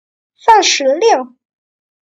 Sānshíliù - Xan sứ liêu )